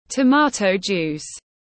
Nước ép cà chua tiếng anh gọi là tomato juice, phiên âm tiếng anh đọc là /təˈmɑː.təʊ ˌdʒuːs/